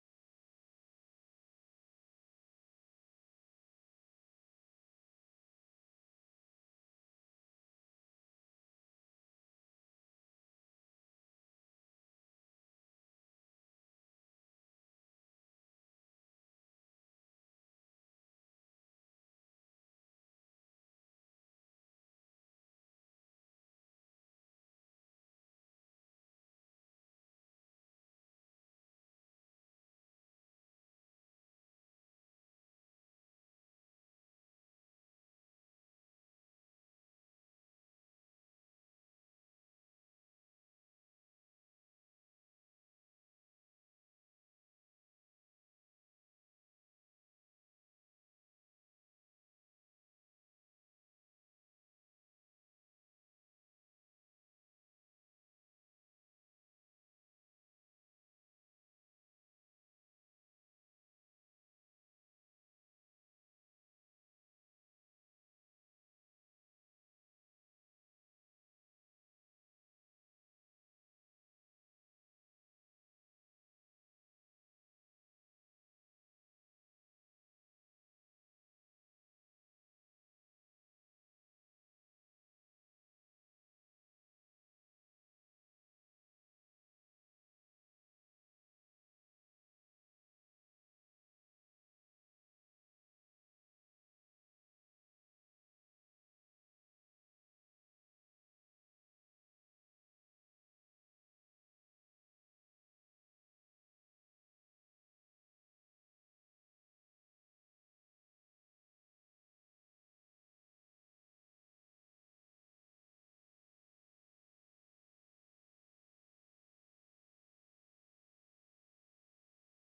June 30th 2024 Sunday Worship
Praise Worship